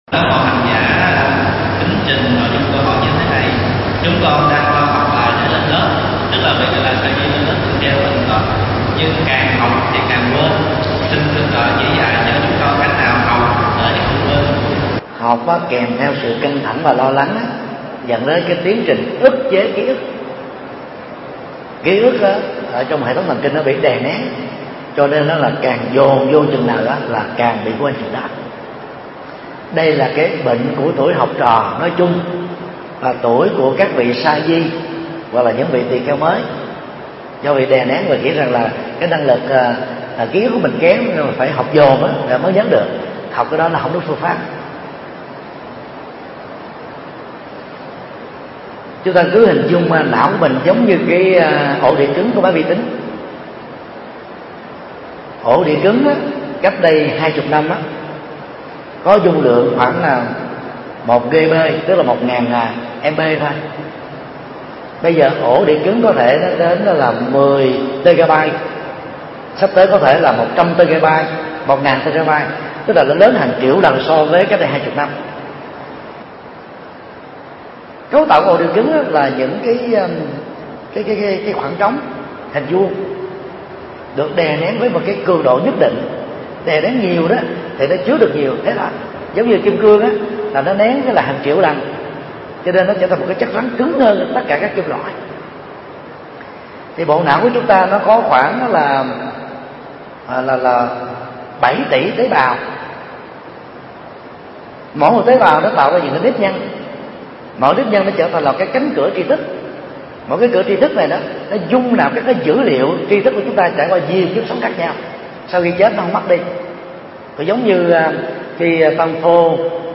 Vấn đáp: Phương pháp học Phật đạt hiệu quả